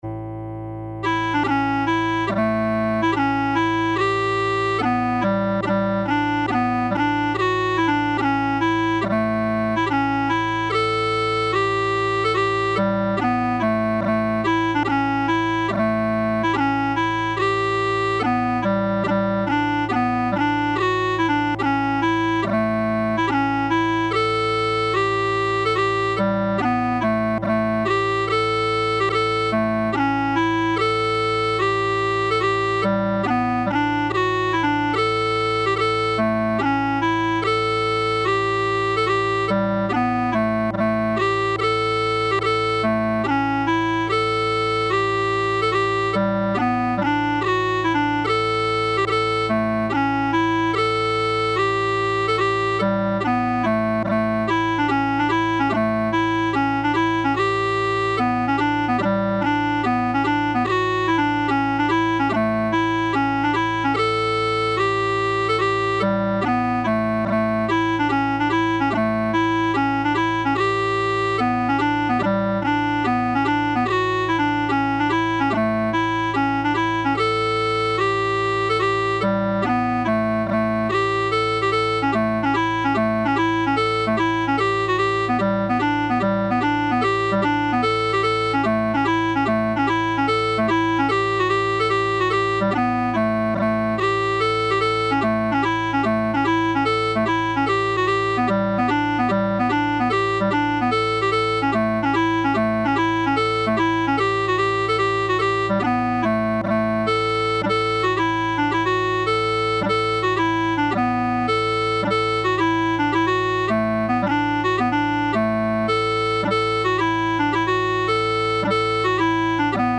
March: The Battle of Tamai
Strathspey: O'er the Bows to Ballindalloch
Reel: Lexy McAskill